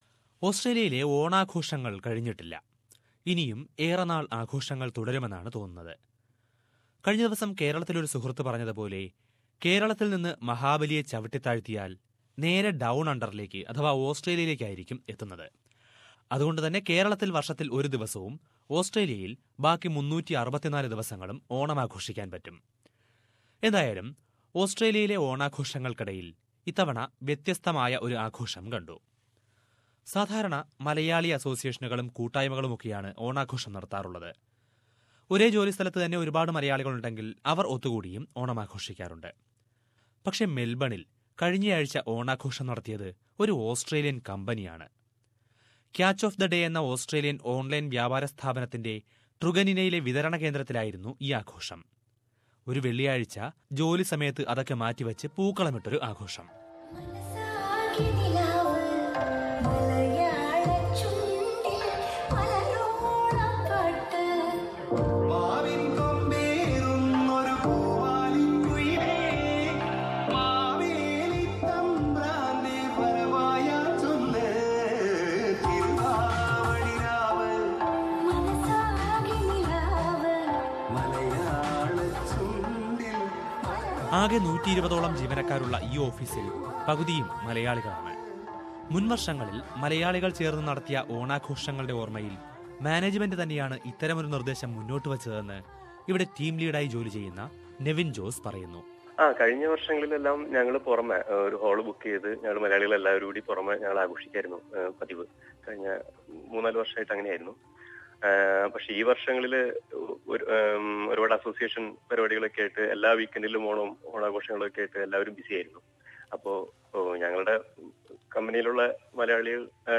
An Australian company organised Onam celebration for the staff. Listen to a report on that.